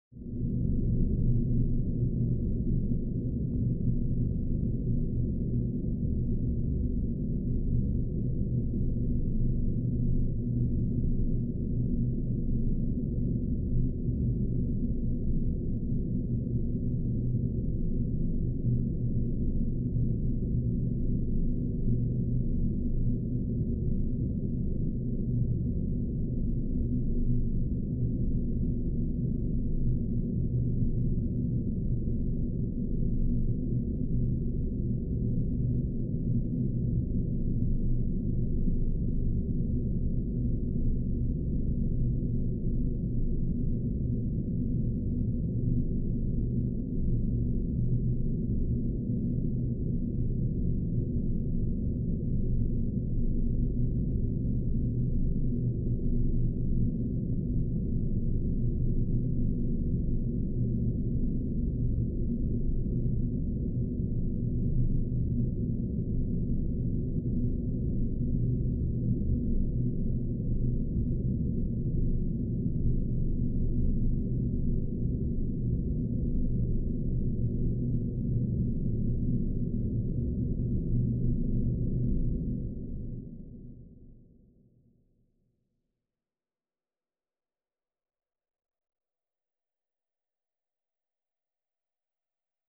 dark_house.wav